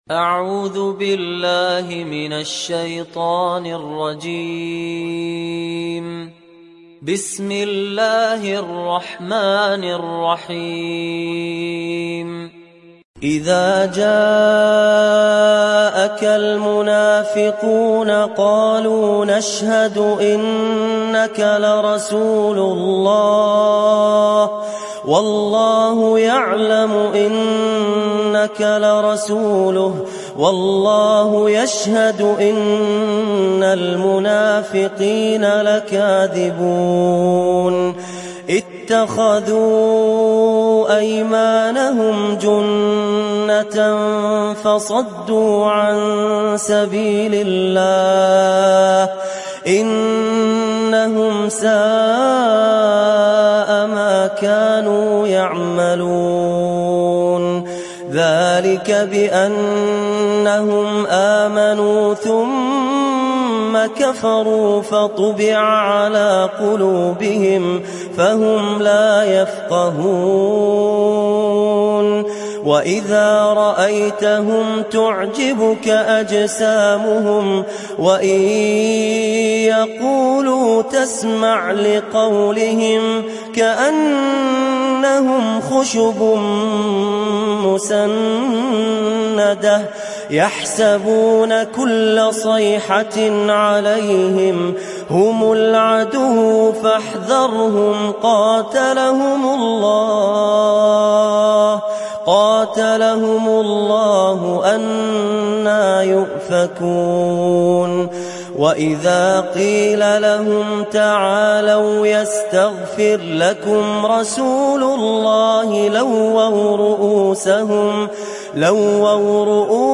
تحميل سورة المنافقون mp3 بصوت فهد الكندري برواية حفص عن عاصم, تحميل استماع القرآن الكريم على الجوال mp3 كاملا بروابط مباشرة وسريعة